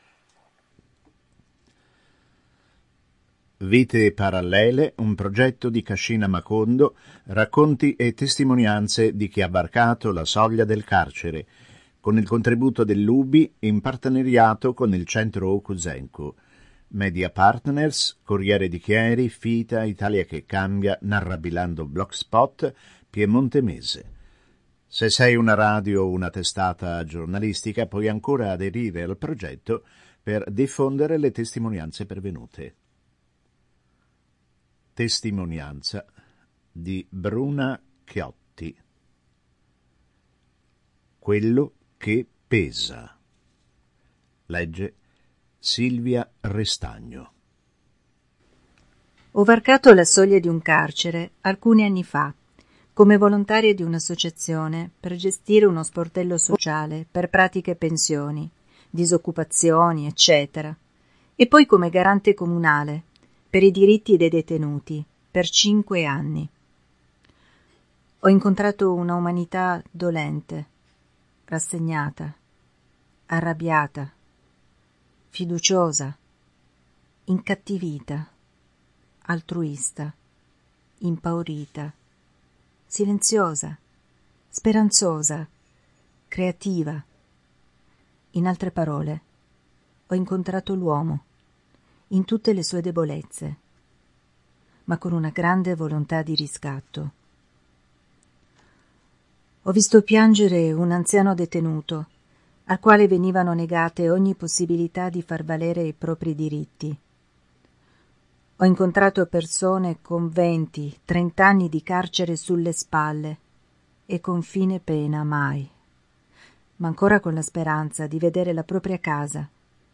il file audio, con testo registrato, in formato mp3.